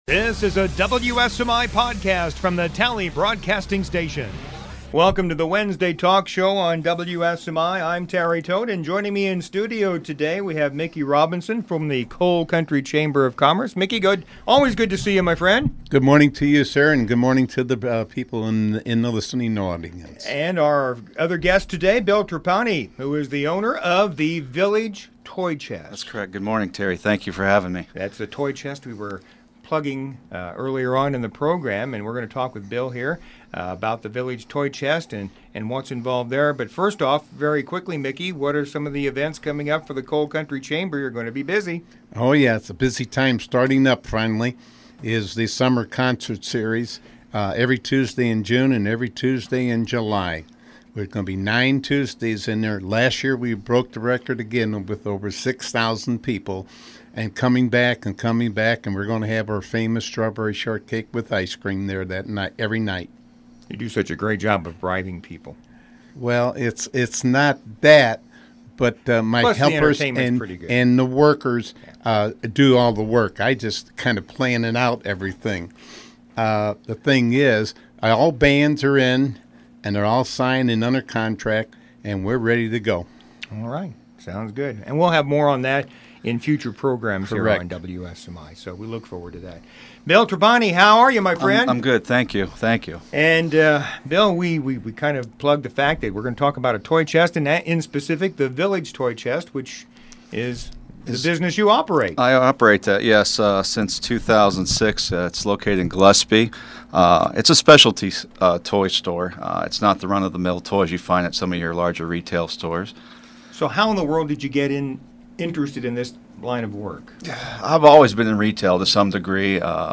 Wednesday Talk Wednesday Talk Show 04/22/2015 Wednesday Talk Show Guests